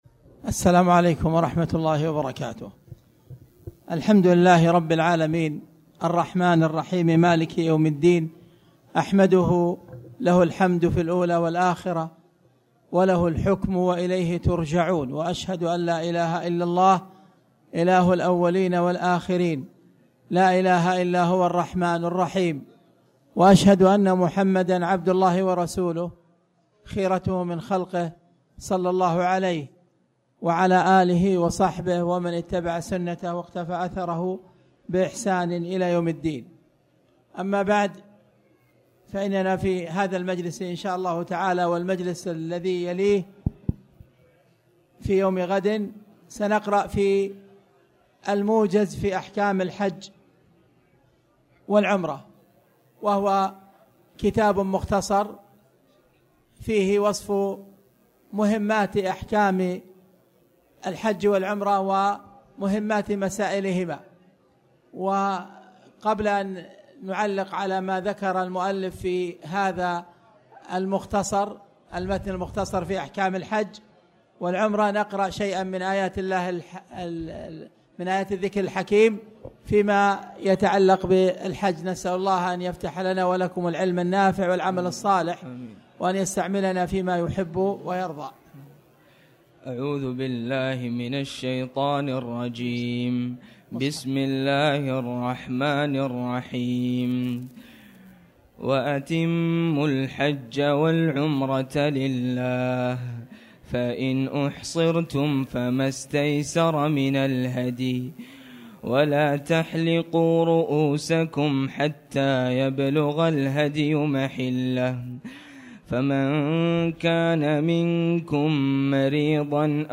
تاريخ النشر ١٠ ذو القعدة ١٤٣٩ هـ المكان: المسجد الحرام الشيخ